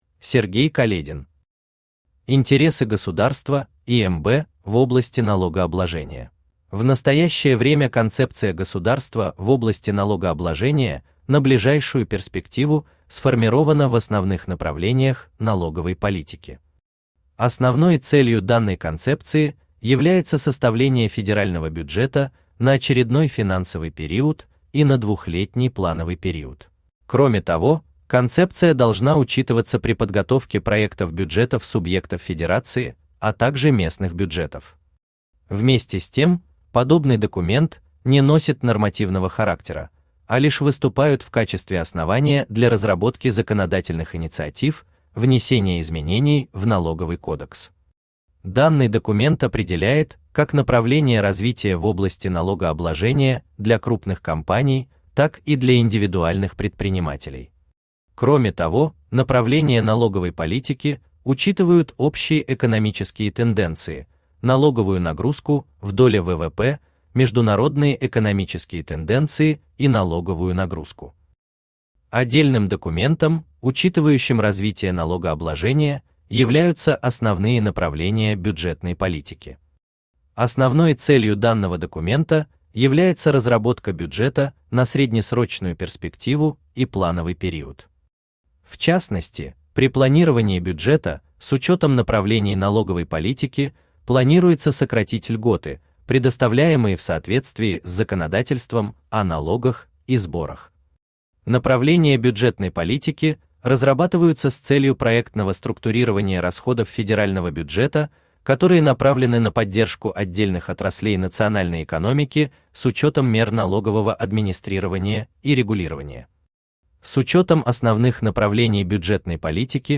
Аудиокнига Интересы государства и МБ в области налогообложения | Библиотека аудиокниг
Aудиокнига Интересы государства и МБ в области налогообложения Автор Сергей Каледин Читает аудиокнигу Авточтец ЛитРес.